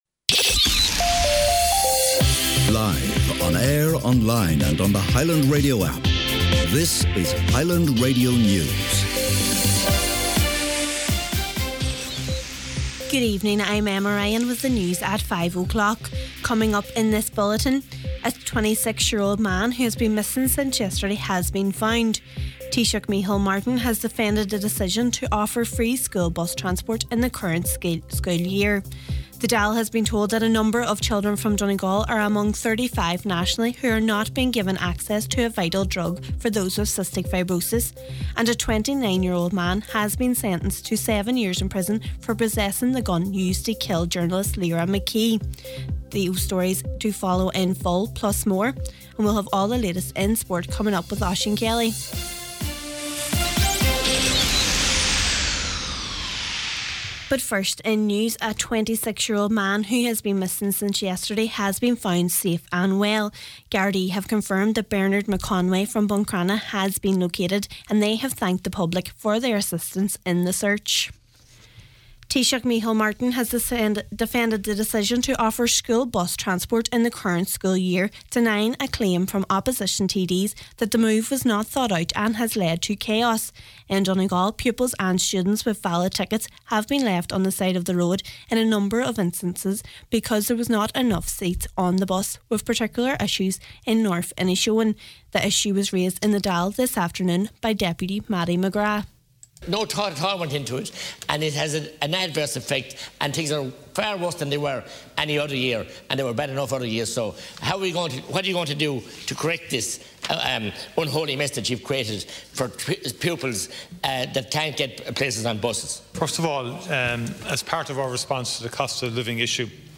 Main Evening News, Sport and Obituaries – Wednesday September 14